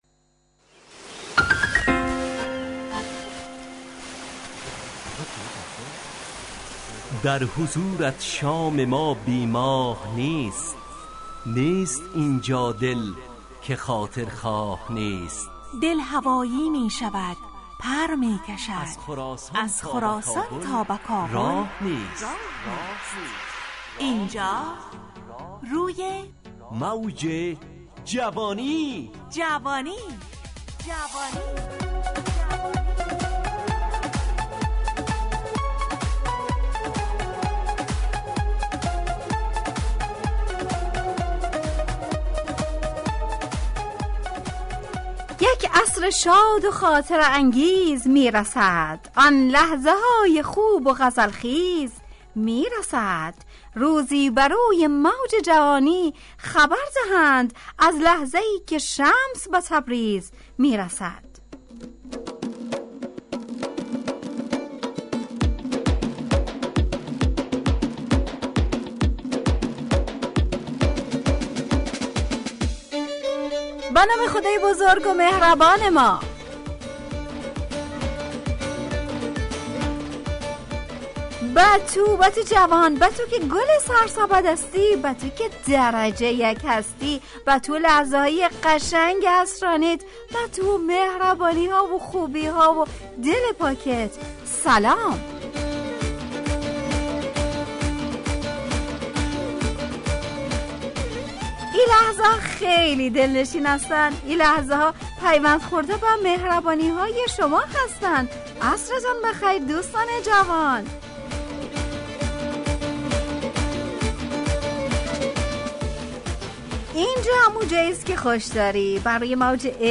روی موج جوانی، برنامه شادو عصرانه رادیودری.